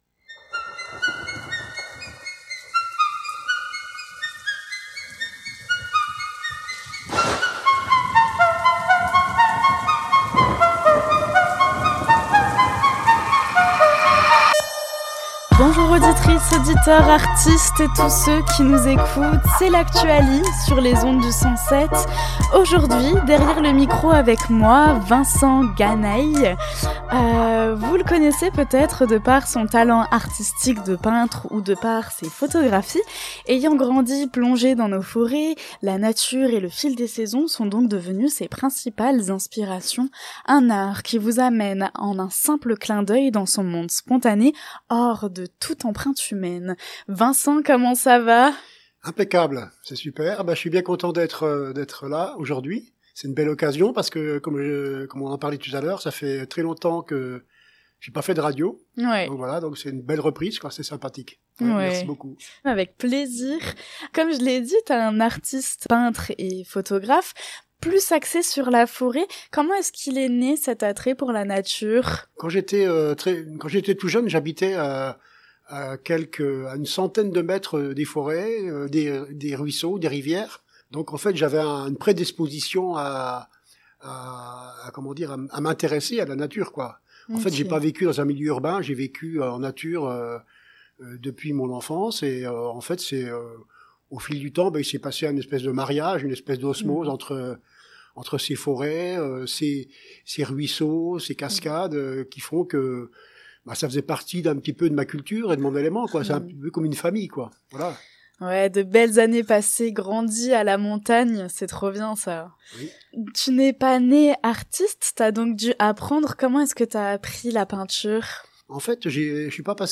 J'ai invitée dans mon émission un artiste amoureux de nôtre chère mère nature